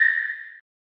sonar7.mp3